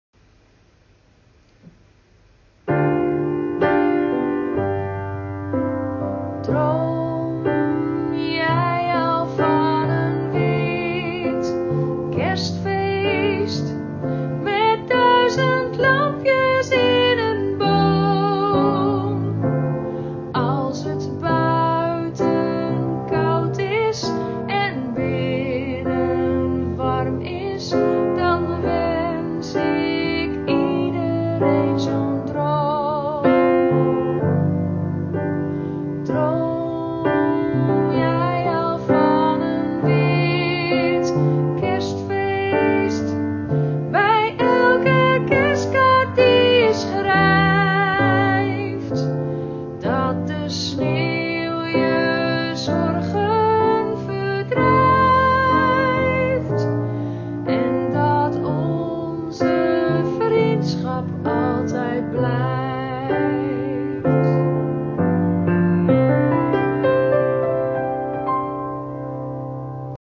gezongen voorbeeld